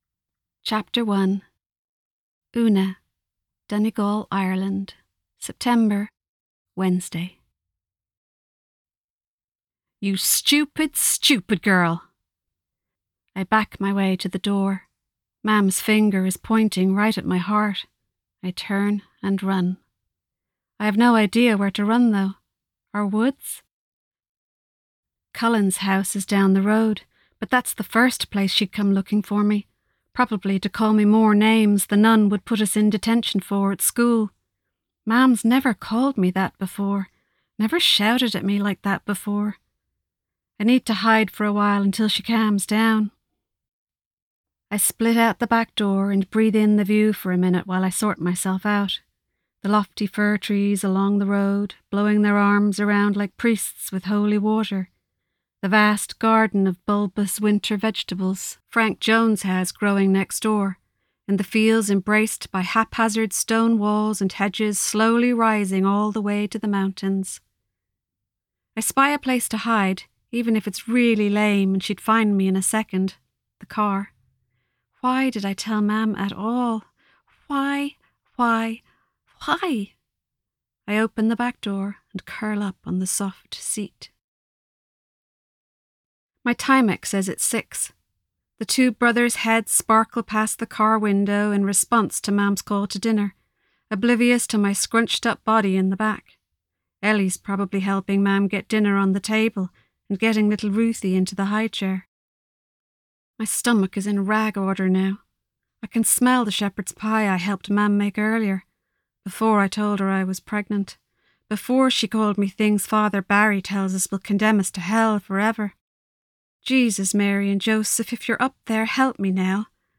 Audiobook of My Heart Went Walking
It's all recorded, and is currently being mastered.
I think it sounds pretty good, although it’s hard to speak with exactly the same accent, pitch, and speed or slowness for every character through 82,000 words.